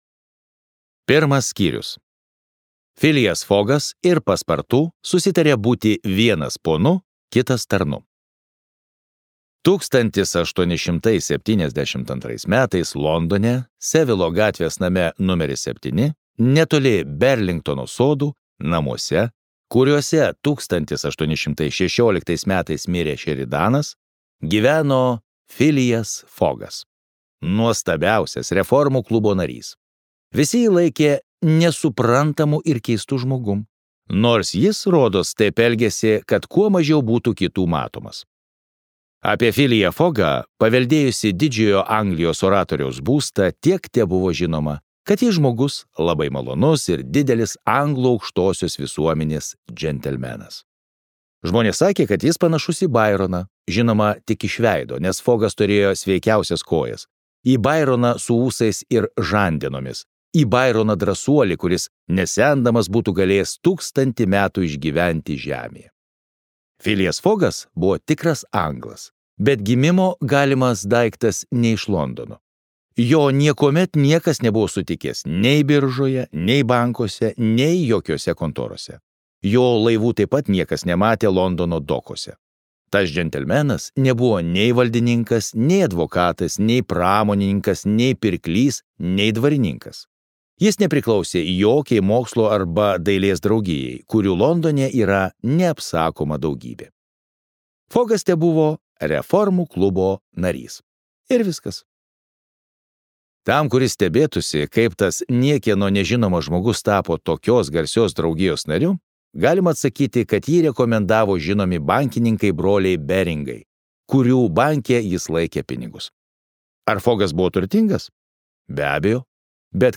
Aplink Žemę per 80 dienų | Audioknygos | baltos lankos